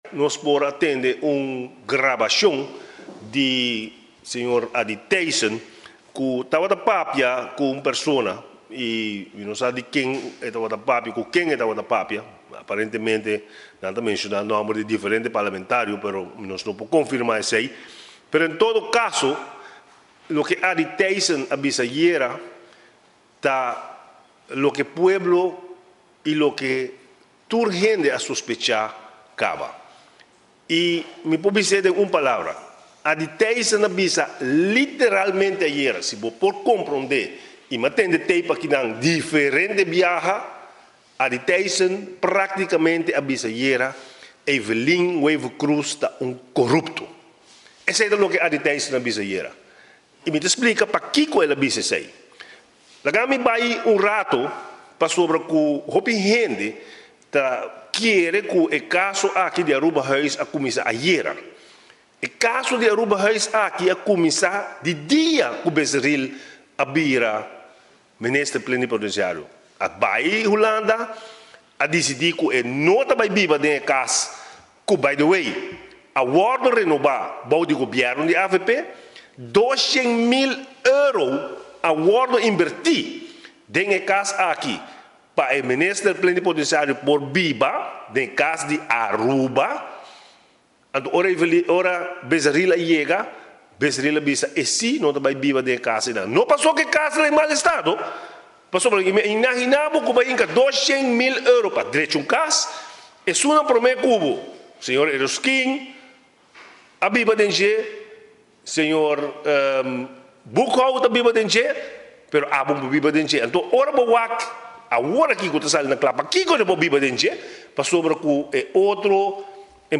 Parlamentario Benny Sevinger den conferencia di prensa a elabora riba un grabacion cu a Sali afo riba minister Plenipotenciario Andy Thijsen.